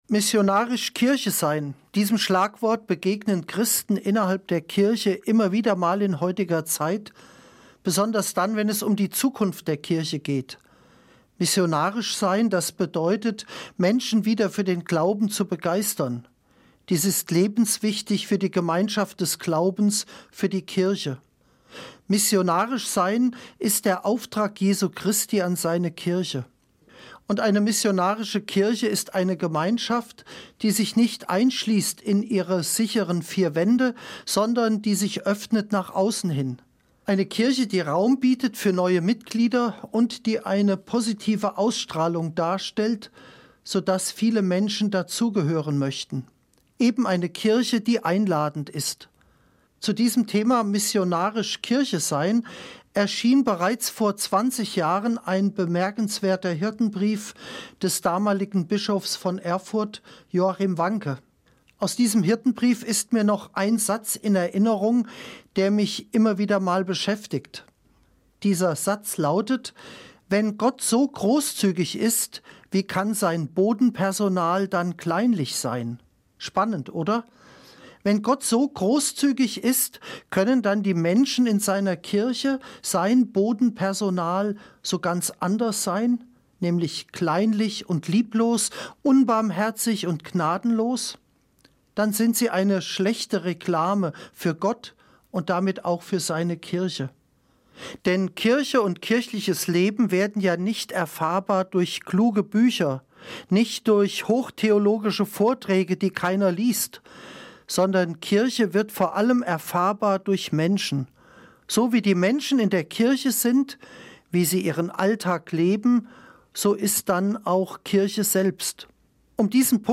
Musik: Bassani Sinfonie Op.5 – Sonata No.4 in D